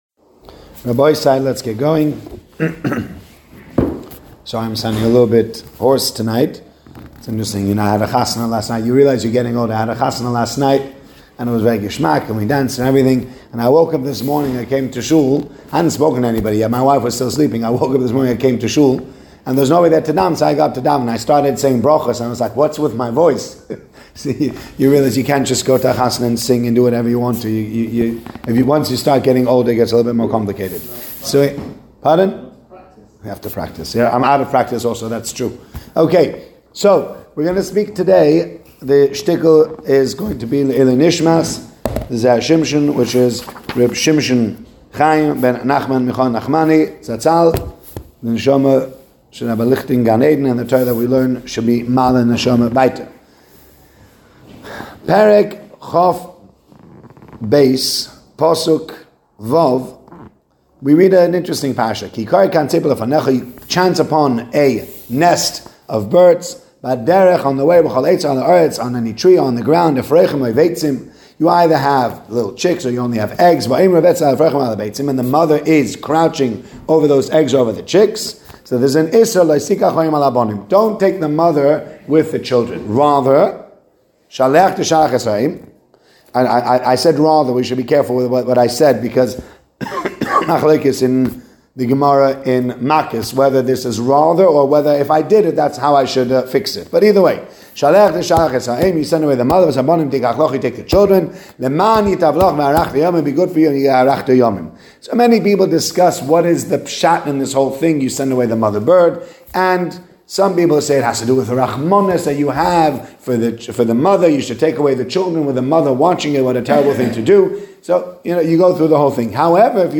shiur